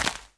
grass.3.ogg